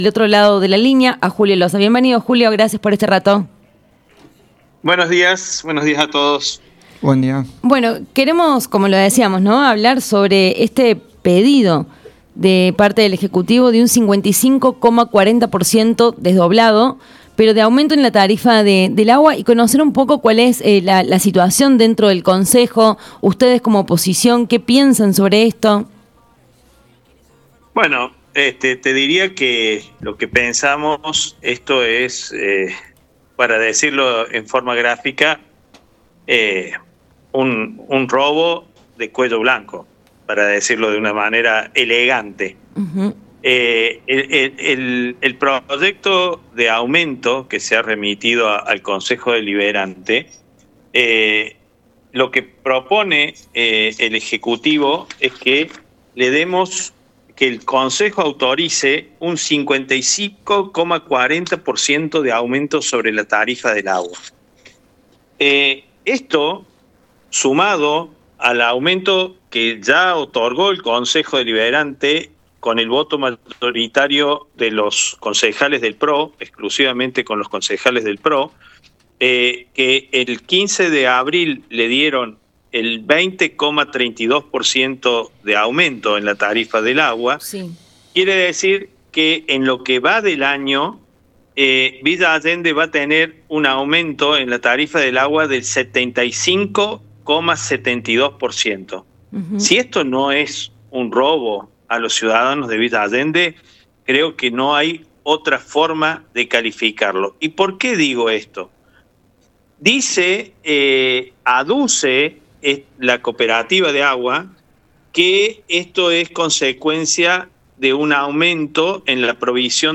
ESCUCHA LA NOTA COMPLETA A JULIO LOZA